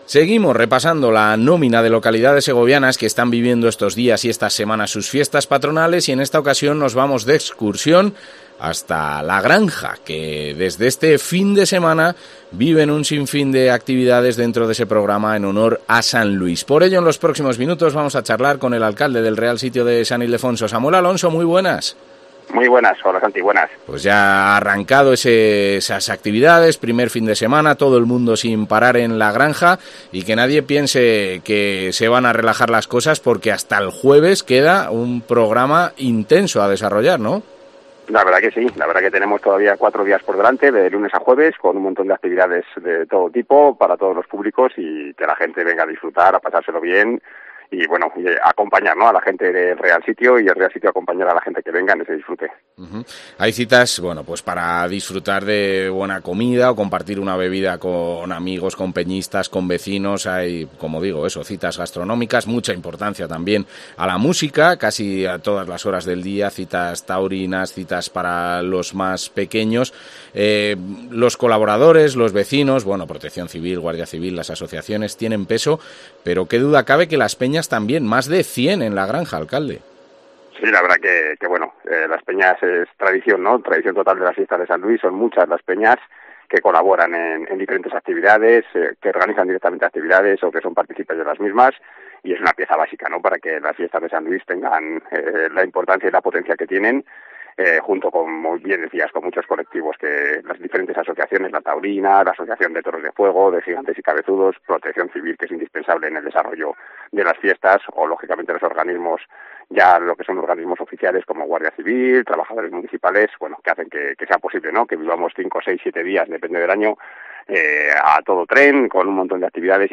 El alcalde del Real Sitio de San Ildefonso, Samuel Alonso, sobre las fiestas de San Luis 2022, en La Granja